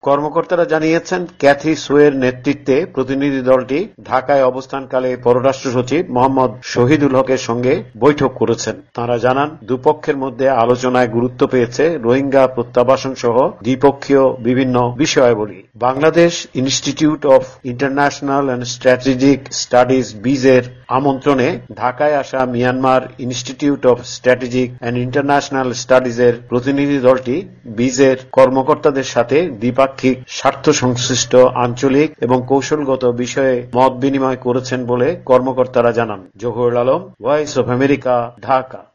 ঢাকা থেকে